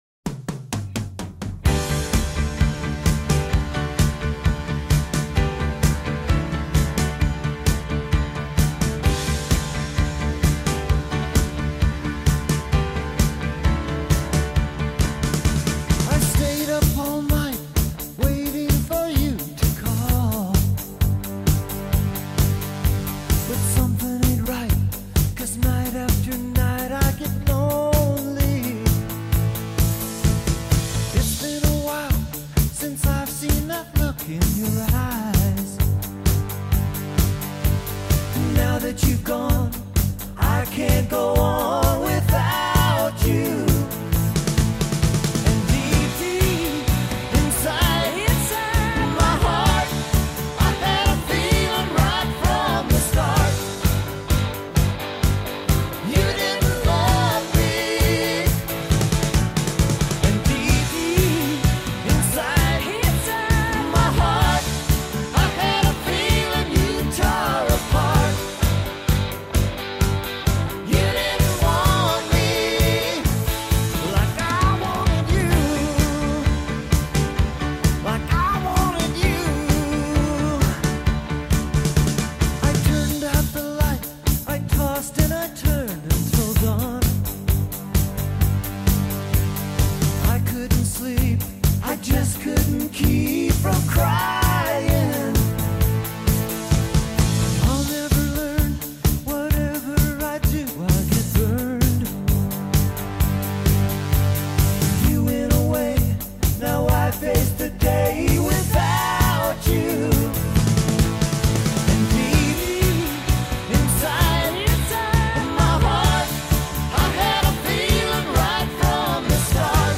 Studio musicians.
percussion
bass
guitar
drums
keyboards